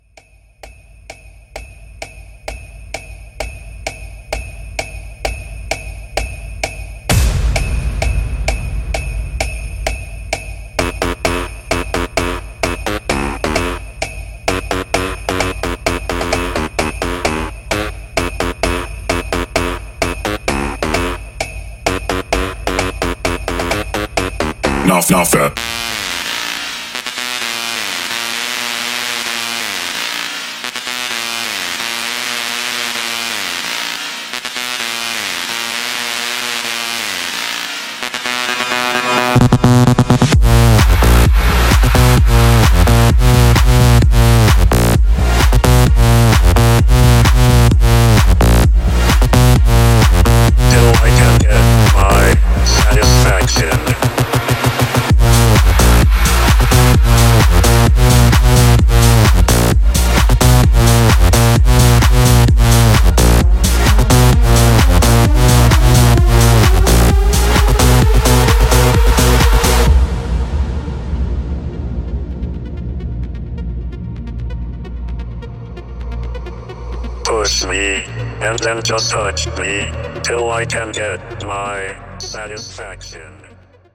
Genres: HIPHOP , RE-DRUM , TOP40
Clean BPM: 102 Time